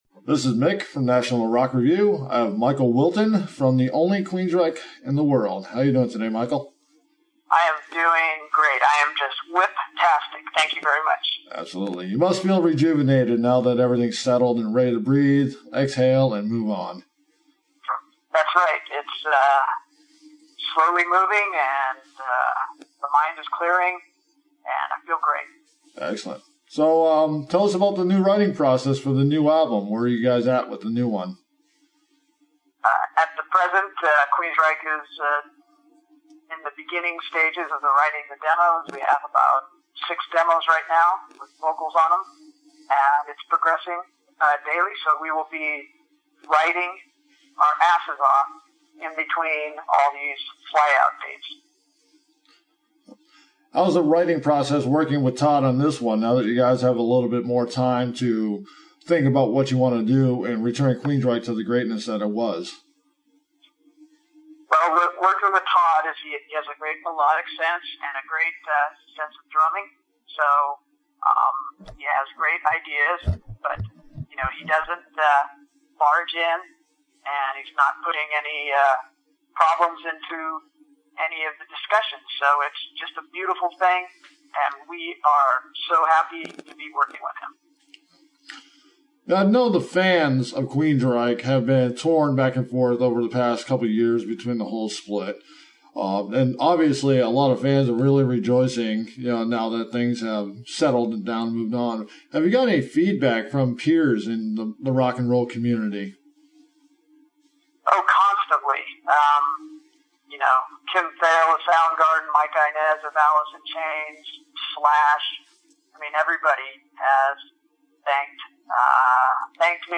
We took time to speak with founding guitarist Michael Wilton over the phone today in Seattle, where he was spending time to clear his mind, rejuvenate, and focus on the writing process of the highly anticipated album due out from the band in summer 2015 while preparing to tour throughout the rest of the year.